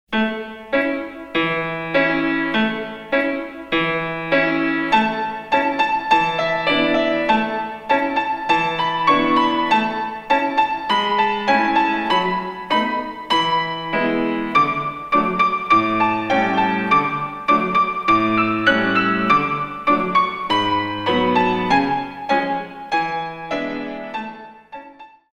In 2